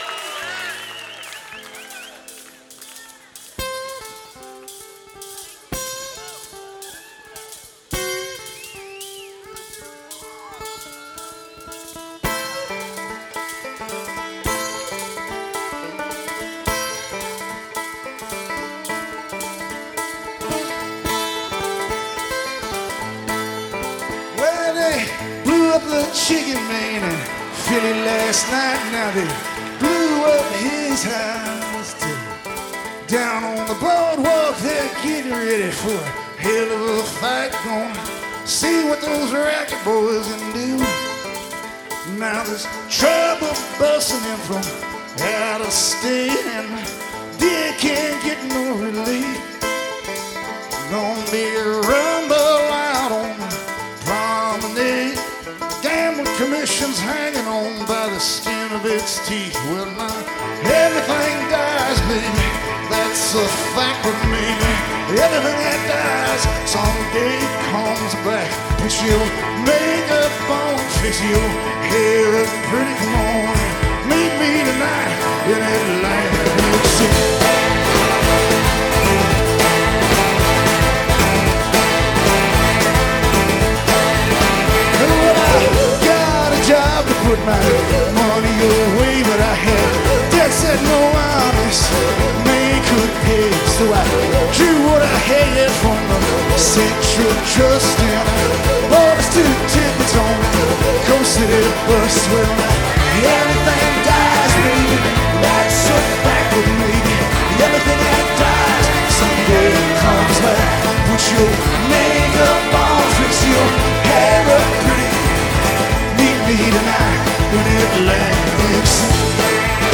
from London 2006
banjo, washboard, horns, backing singers